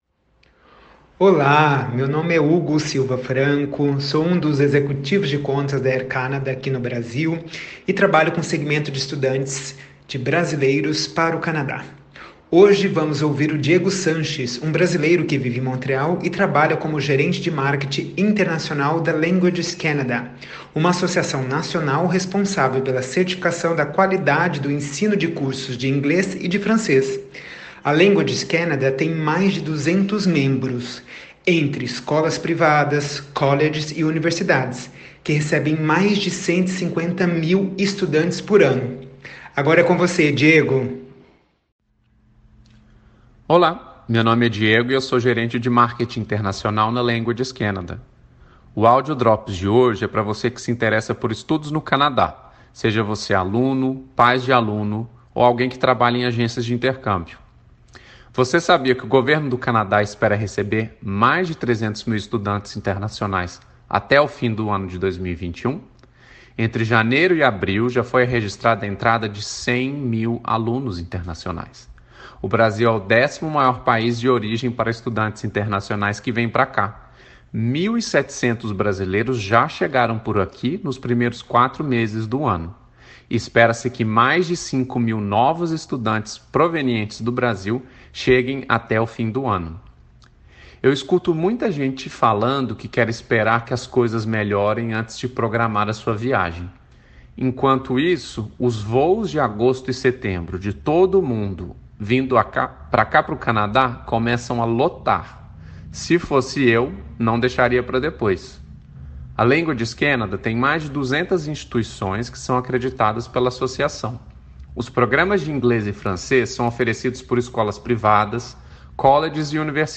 CONVERSA